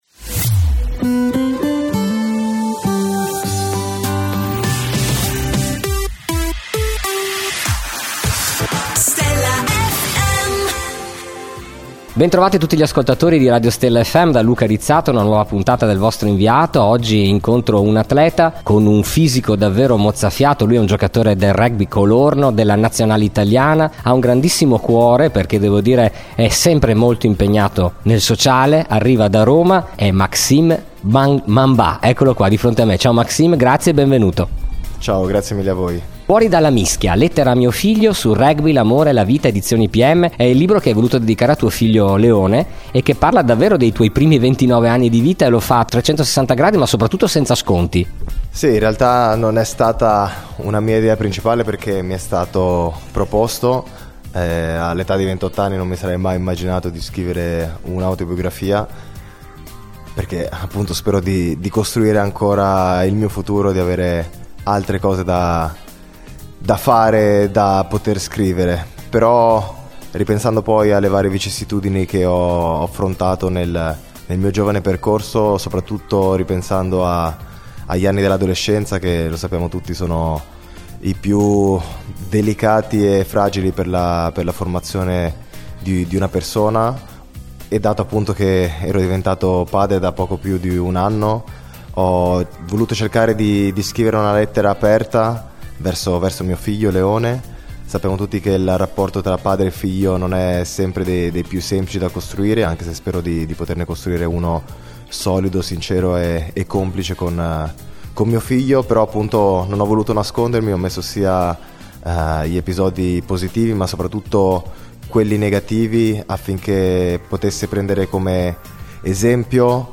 Intervista esclusiva dell’inviato per Stella FM a Maxime Mbanda.